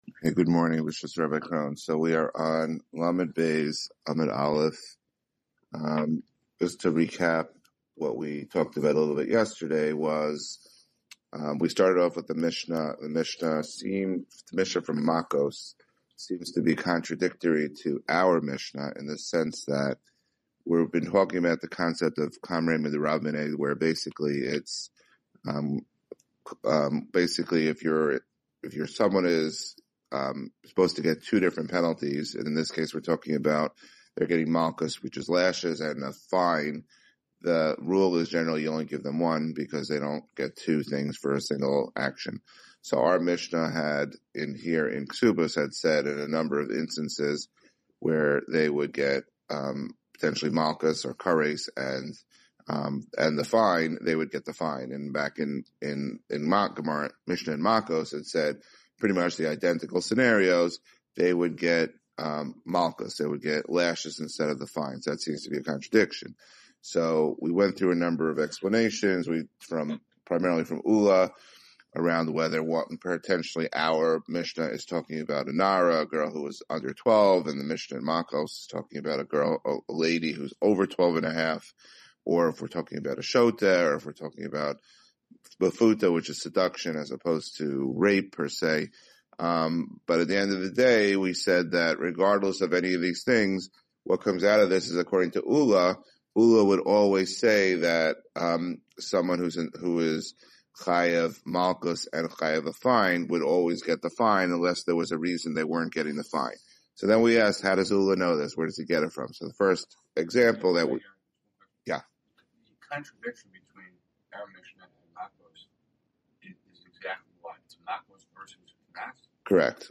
Guest shiur